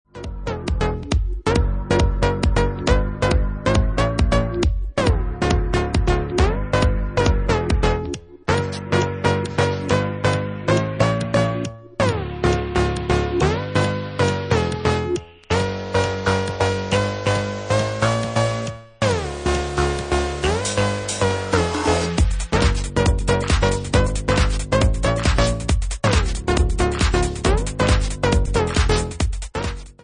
Bassline House at 137 bpm